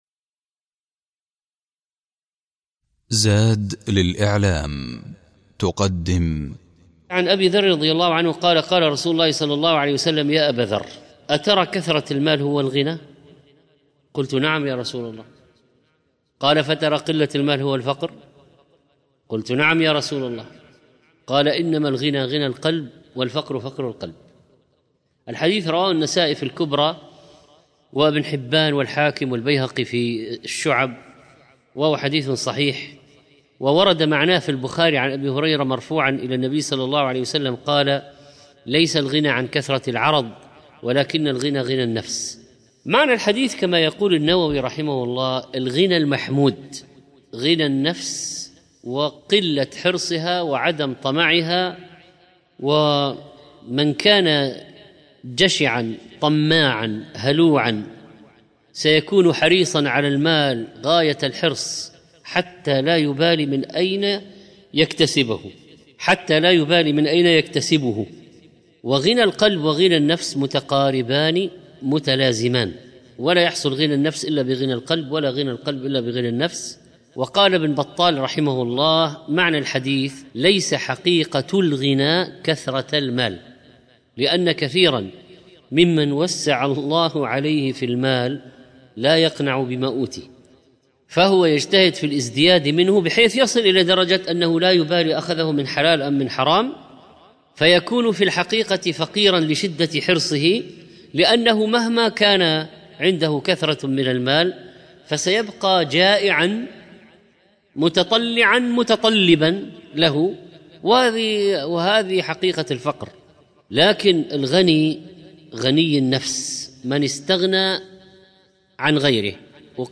08 شوّال 1437 الزيارات: 5110 تحميل تحميل ملف فيديو تحميل ملف صوتي 23- الأربعون القلبية 23، شرح حديث (إنما الغنى غنى القلب والفقر فقر القلب ..)